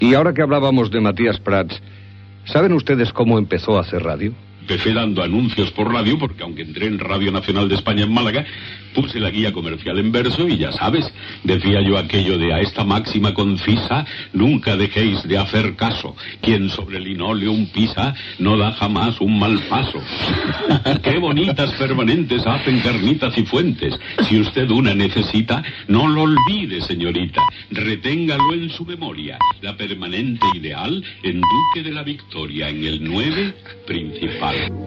Matías Prats recorda els seus inicis a la ràdio de Màlaga, quan feia publicitat
Divulgació